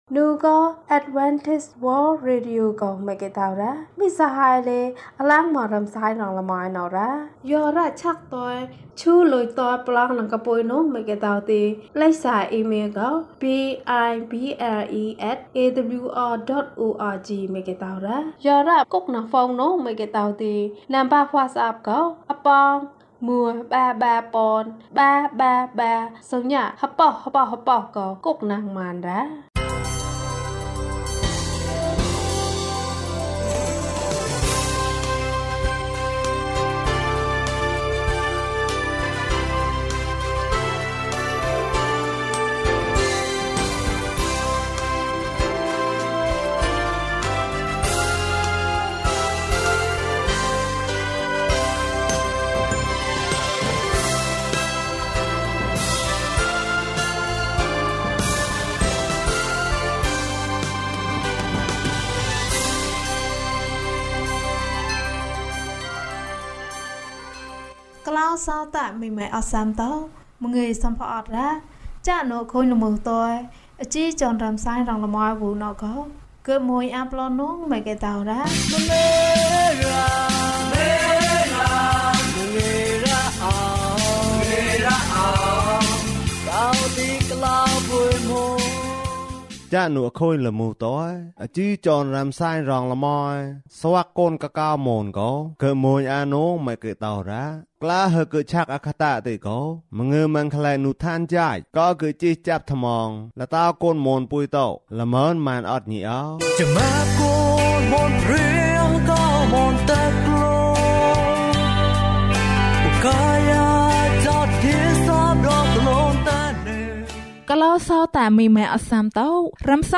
မြတ်သောမေတ္တာ။ ကျန်းမာခြင်းအကြောင်းအရာ။ ဓမ္မသီချင်း။ တရားဒေသနာ။